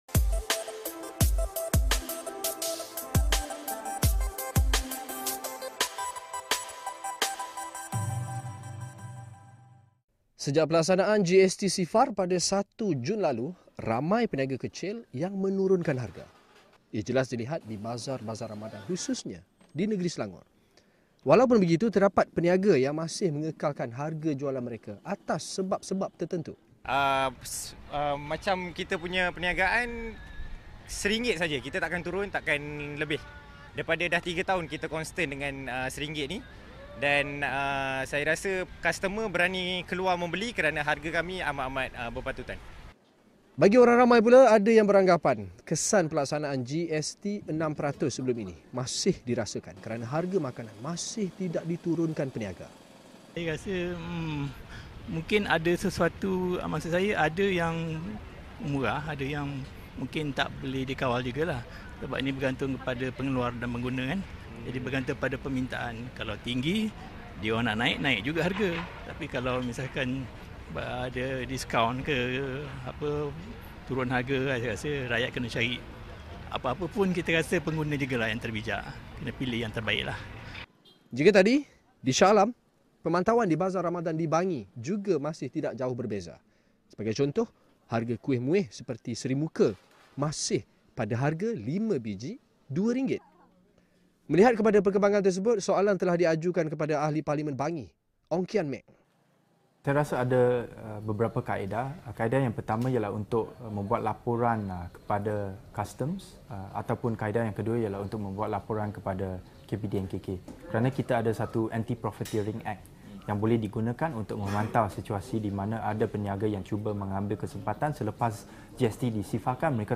Ahli Parlimen Bangi, Ong Kian Ming turut memberi reaksi terhadap peniaga-peniaga yang gagal menurunkan harga barang.